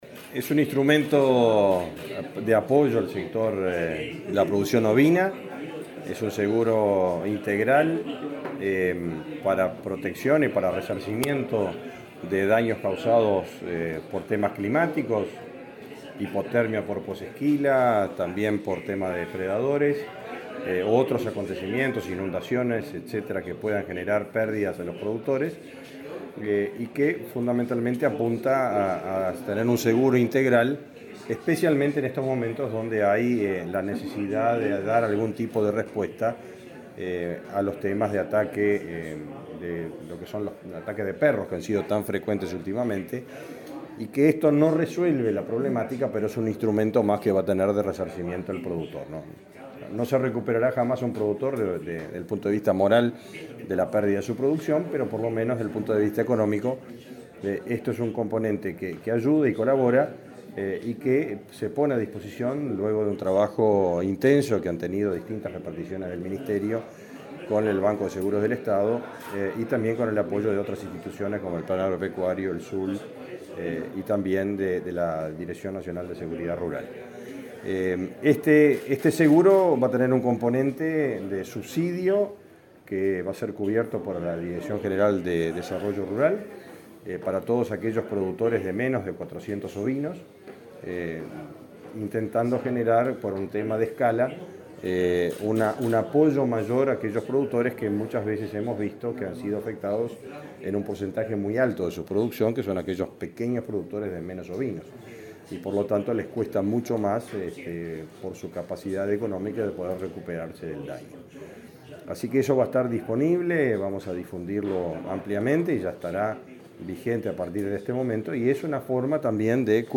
Declaraciones a la prensa del ministro de Ganadería, Fernando Mattos
Declaraciones a la prensa del ministro de Ganadería, Fernando Mattos 28/07/2022 Compartir Facebook X Copiar enlace WhatsApp LinkedIn El presidente del Banco de Seguros del Estado (BSE), José Amorín Batlle, y el ministro de Ganadería, Fernando Mattos, firmaron un convenio para instrumentar un seguro para productores ovinos. Luego, Mattos dialogó con la prensa.